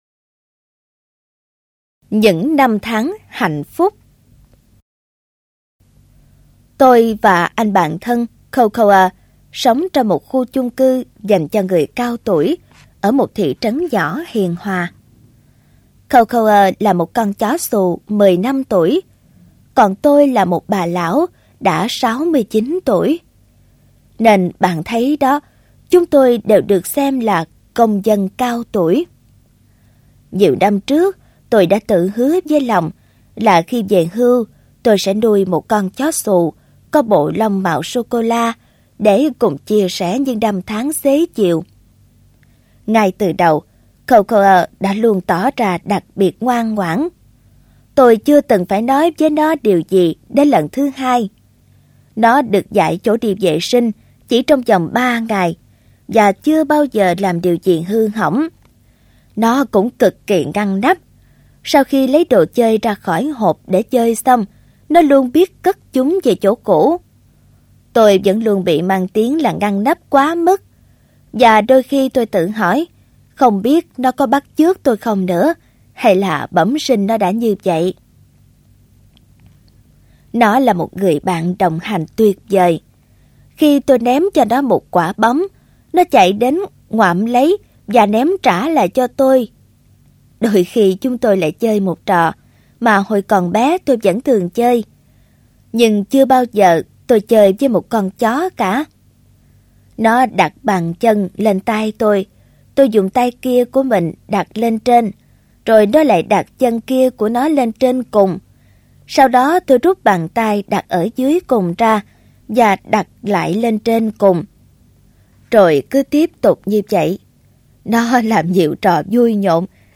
Trang Audio Books